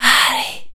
WHISPER 05.wav